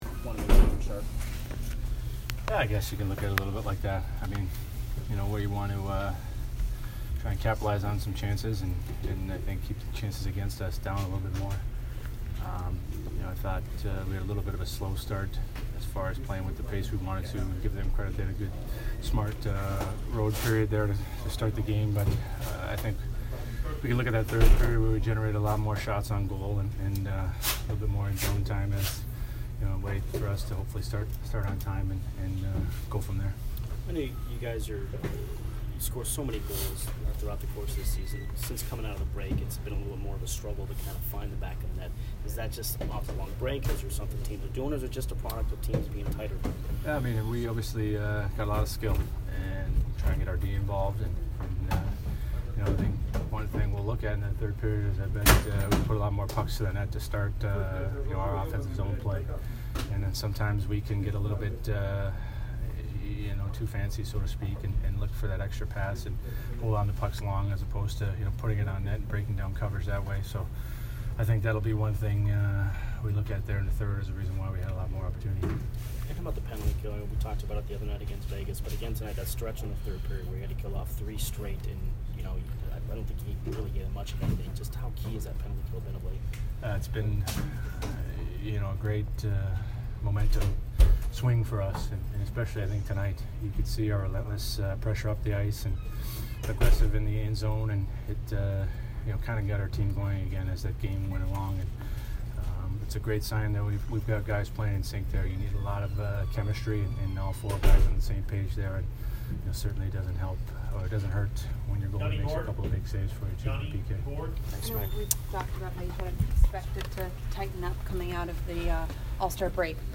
Ryan McDonagh post-game 2/7